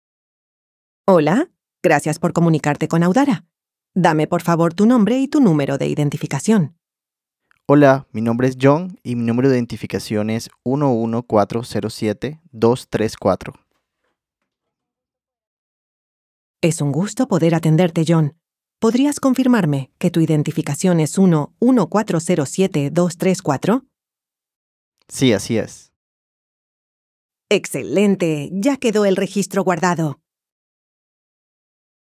A continuación, podrás escuchar ejemplos reales de interacción con nuestros voicebots, en audios donde las voces responden y conversan en tiempo real.
➡ Ejemplo Voicebot: Interacción de un cliente con un Voicebot con acento mexicano